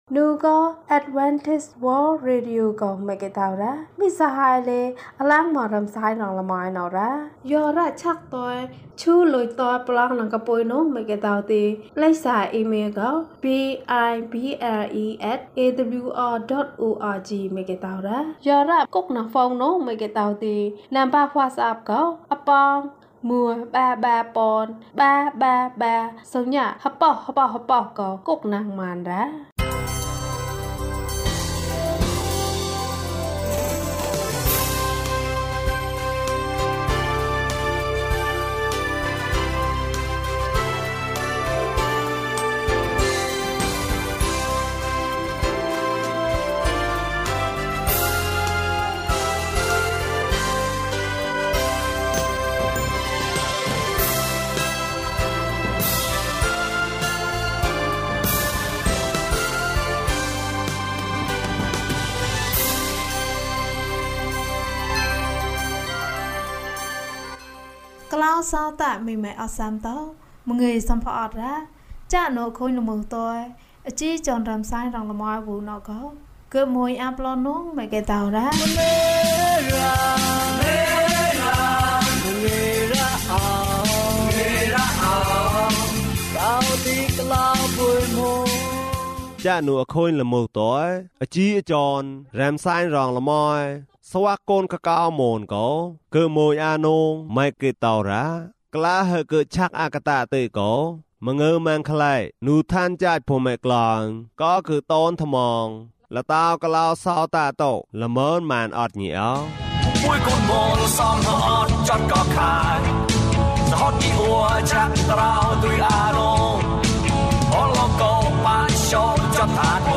ခရစ်တော်ထံသို့ ခြေလှမ်း ၂၁။ ကျန်းမာခြင်းအကြောင်းအရာ။ ဓမ္မသီချင်း။ တရားဒေသနာ။